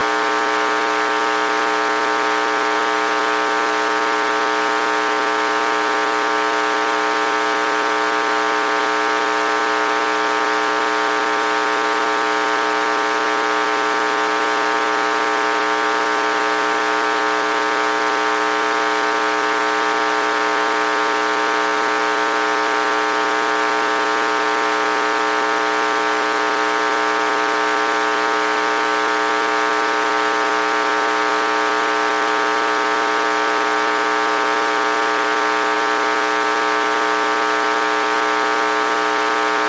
speaking into computer
An algorithm can translate my voice to be more audible. You can also hear some background chatter.